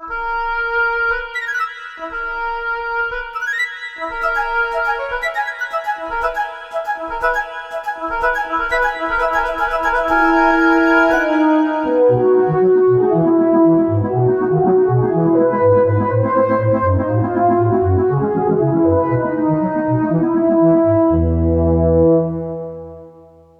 Música programática.
melodía
Sonidos: Música